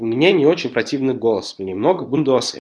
go-speech - TTS service